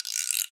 MURDA_PERCUSSION_GUIRO.wav